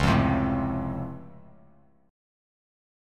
Db+M7 chord